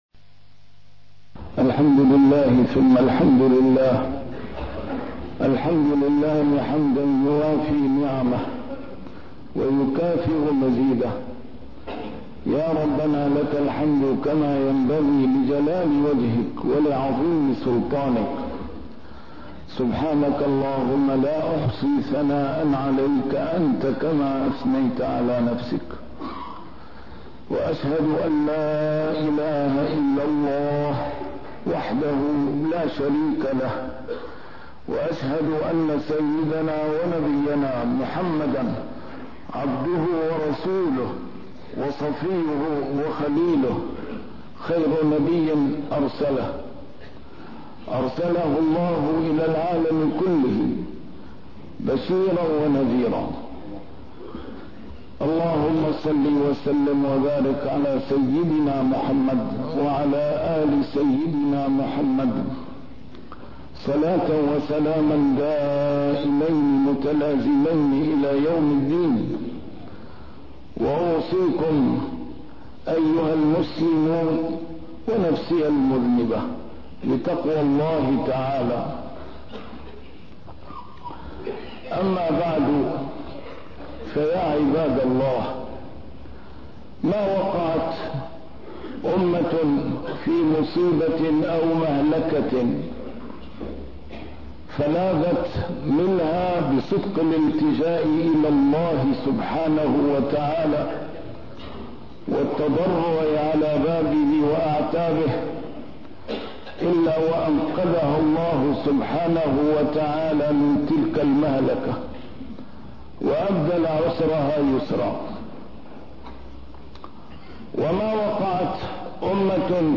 A MARTYR SCHOLAR: IMAM MUHAMMAD SAEED RAMADAN AL-BOUTI - الخطب - فلولا إذ جاءهم بأسنا تضرعوا